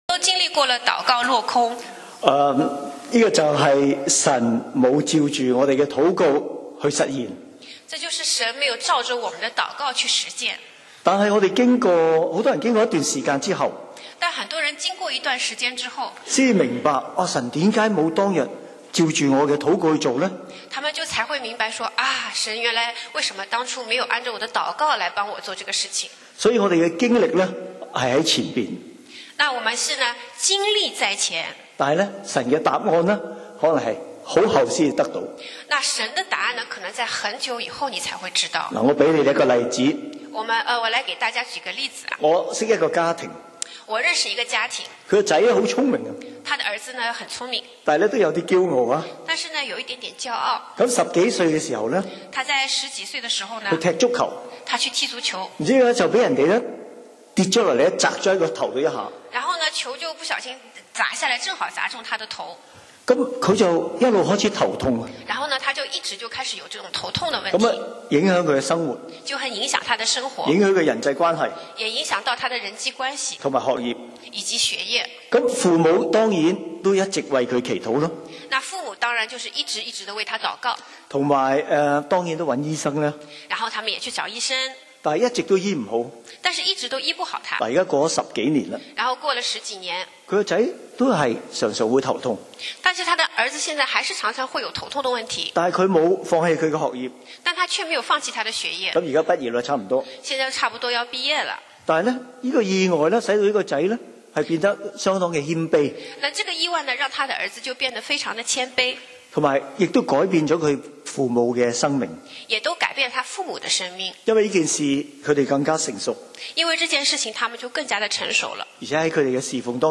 講道 Sermon 題目 Topic： 禱告落空 經文 Verses：約翰福音11:1-53